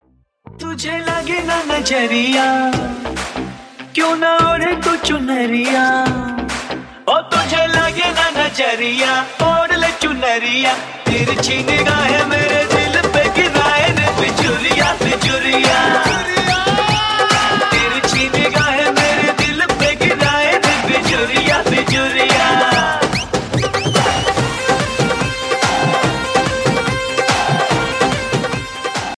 is a vibrant and catchy track